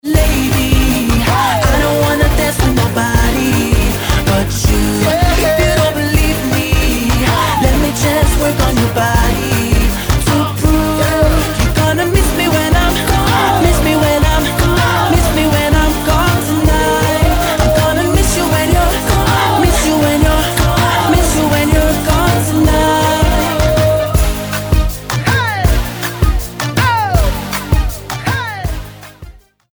Švédsky spevák a tanečník